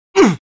Voice file from Team Fortress 2 Spanish version.
Scout_painsharp06_es.wav